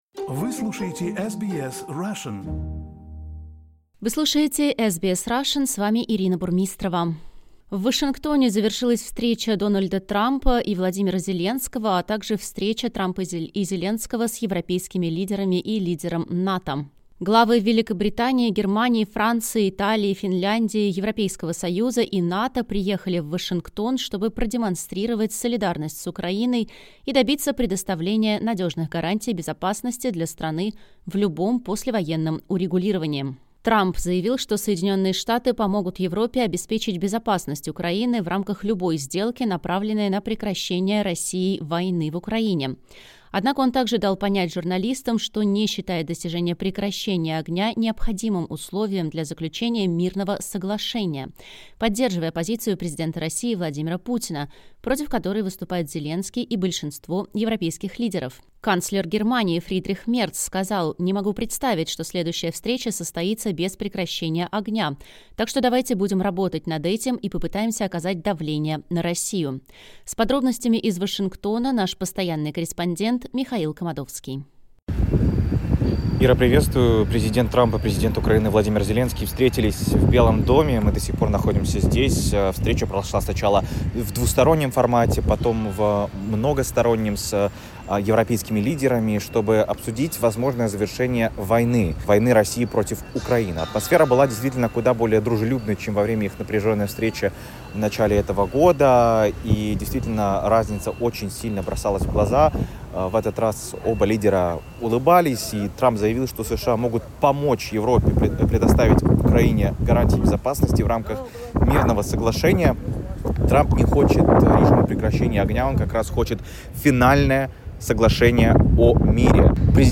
Встреча Трампа, Зеленского и европейских лидеров: репортаж из Вашингтона